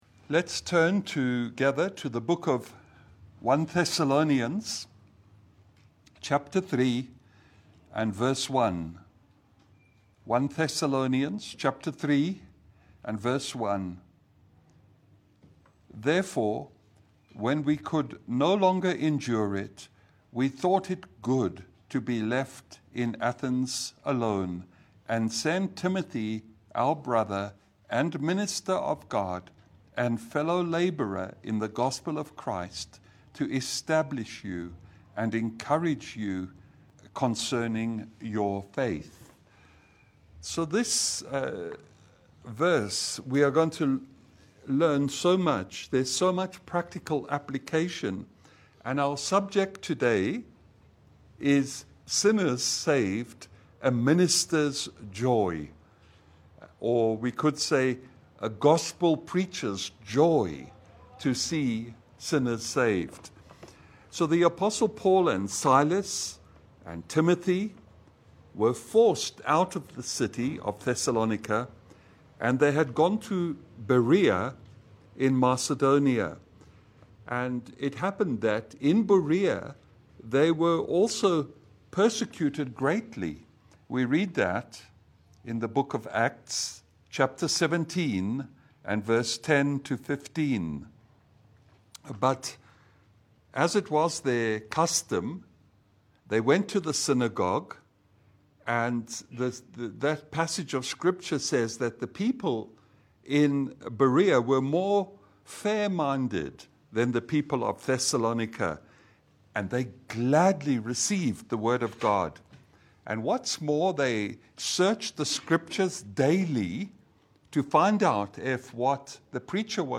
Service Type: Lunch hour Bible Study